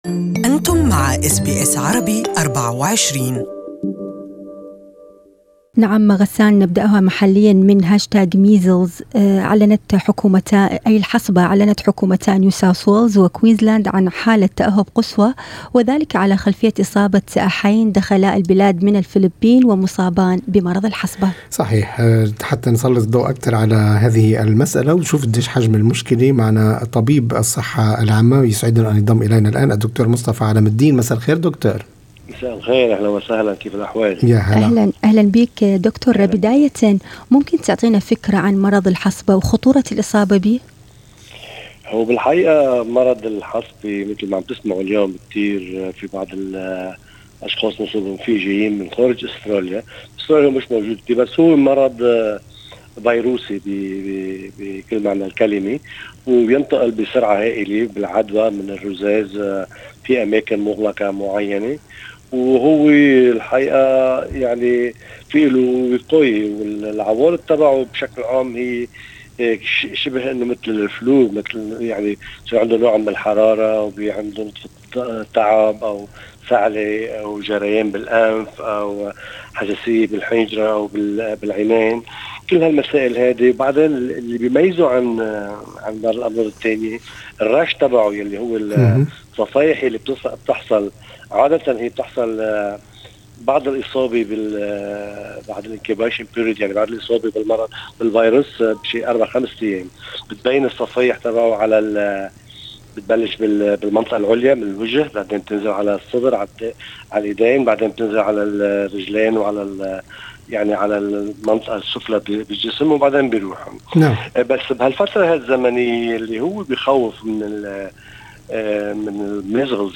المزيد في لقاءِ مباشر